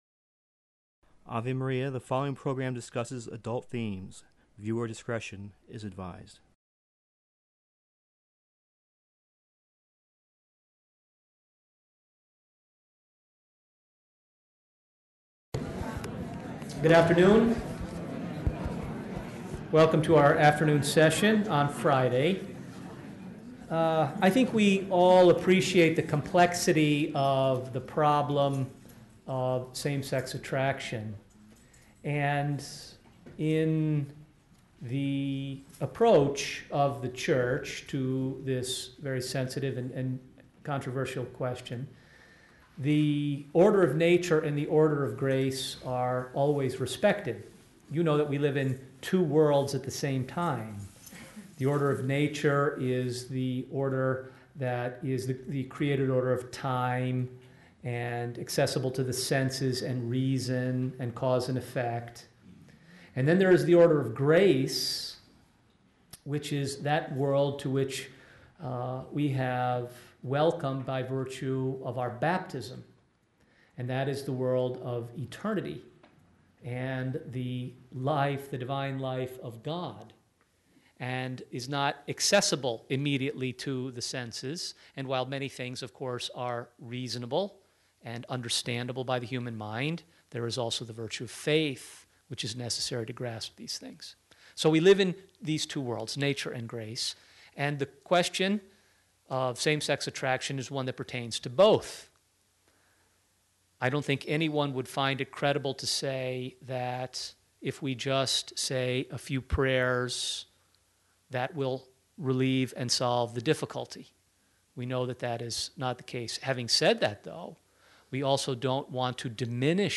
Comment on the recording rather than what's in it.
gives a talk at the 2011 Courage Conference in Chicago